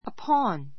upon A2 əpɑ́n ア パ ン 前置詞 on （～の上に） 類似語 on と upon upon は on よりも書き言葉で使われることが多いが, 文の中で on より音の響 ひび きがよい時には話し言葉でも使われる.